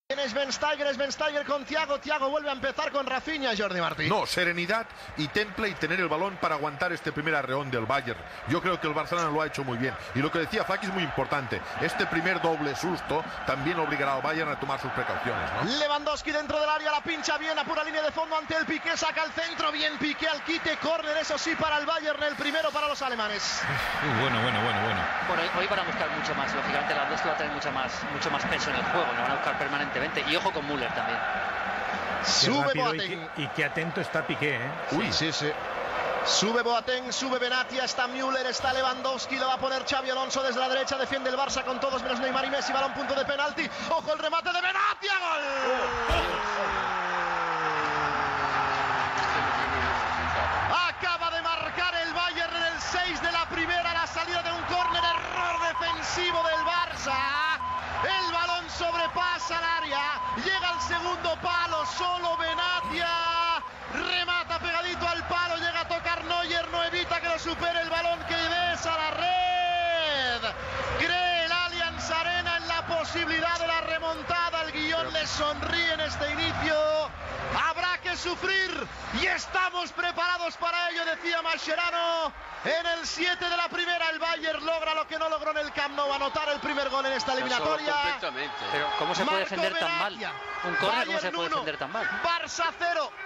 Transmissió del partit de tornada de la fase eliminatòria de la Copa d'Europa de futbol masculí entre el Bayern München i el Futbol Club Barcelona.
Narració d'un parell de jugaddes i del primer gol del Bayern München, marcat per Benatia.
Esportiu